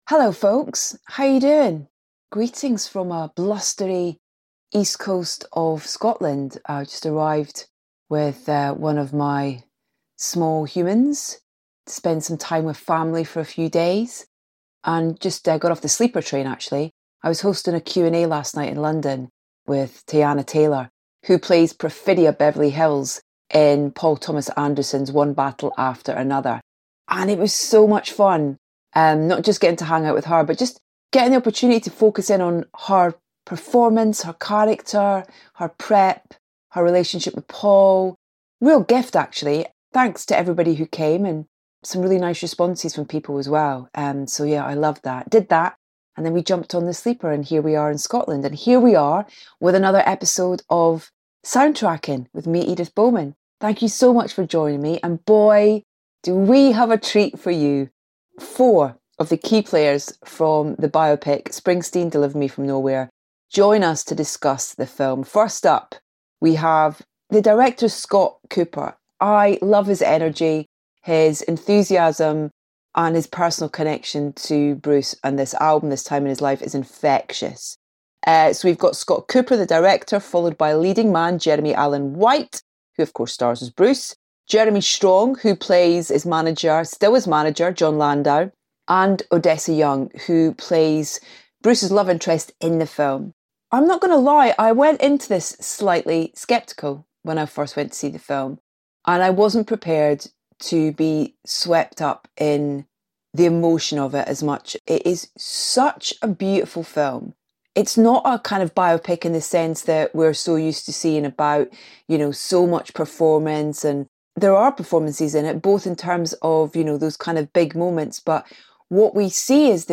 Boy, do we have a treat for you on our latest episode of Soundtracking, as four of the key players from the biopic Springsteen: Deliver Me From Nowhere join us to discuss the film.